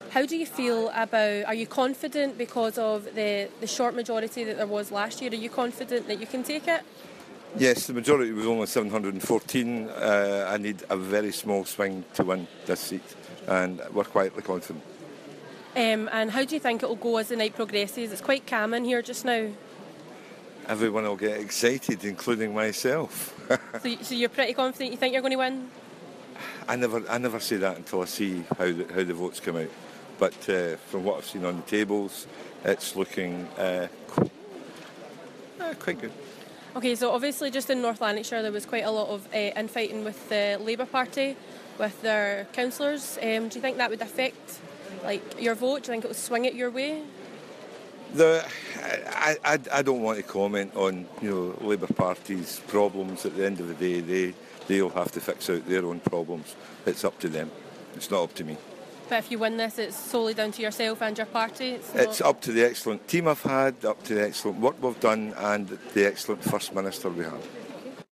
The SNP candidate for uddingston and Bellshill tells our reporter